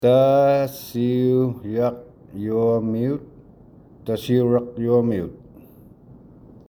Audio clip of how to say Tasiujaqiuaqmiut